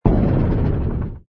engine_ci_freighter_kill.wav